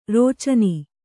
♪ rōcani